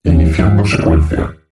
voz nș 0146